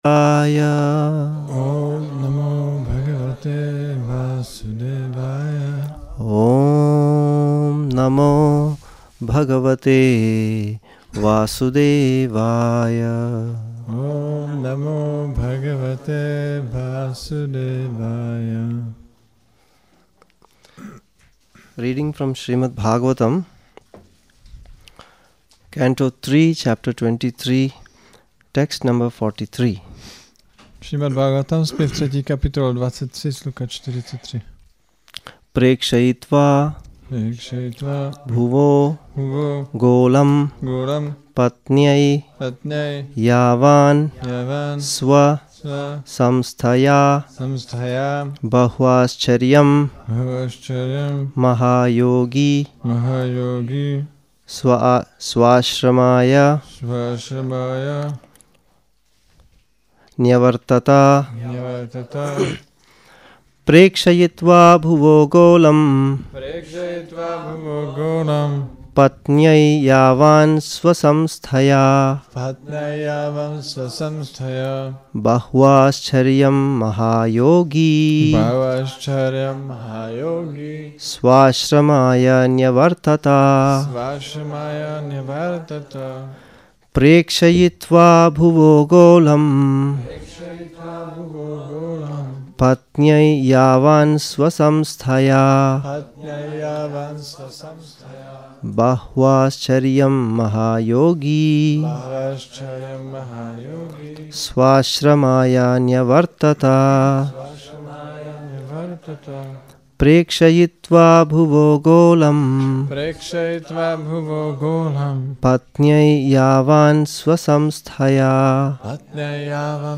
Šrí Šrí Nitái Navadvípačandra mandir
Přednáška SB-3.23.43